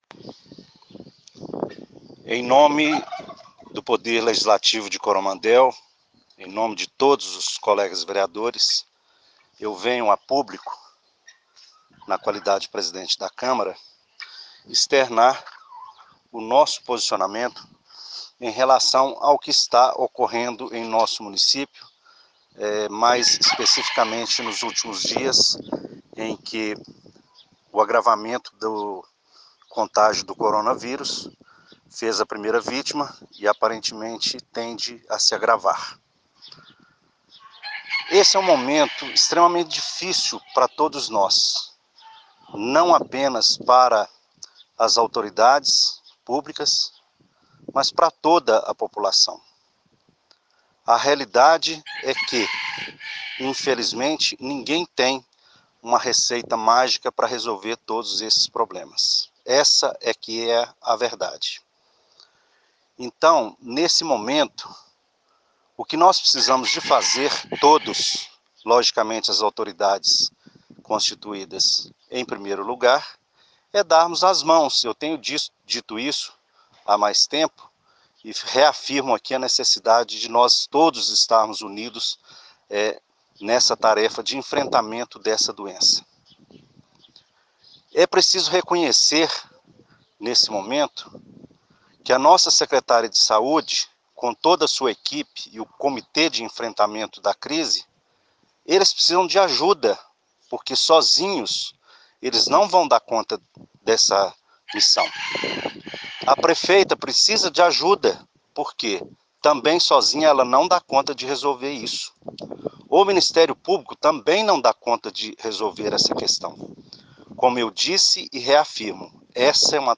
Essa voz ” roca” é da prefeita Dione Pires.👎👇👇👂👂👂
JA ESSA VOZ  ” FALANTE” É DO PRESIDENTE DA CÂMARA, FALA ” BONITO” E ESCONDE A COMPENTENCIA DE AGIR EM A FAVOR DO POVO.👎👇👇👂👂👂